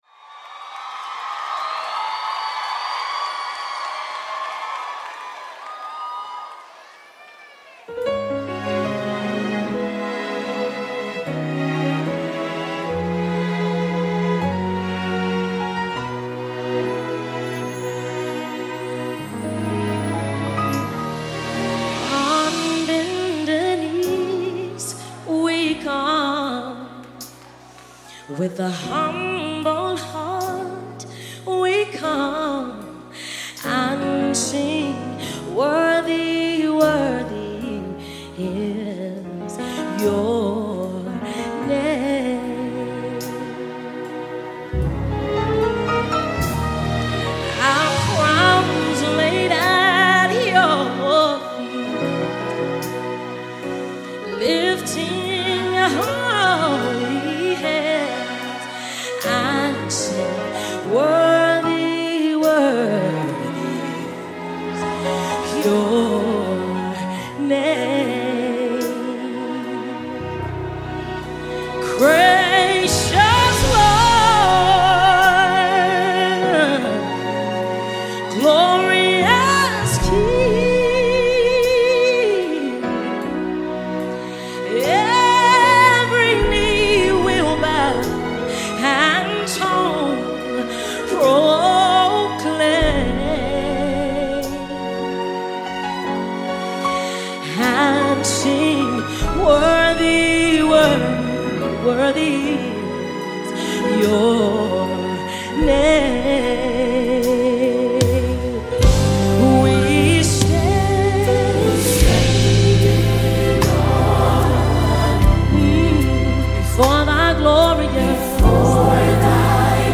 Home » Gospel
It delivers a sound that stays consistent throughout.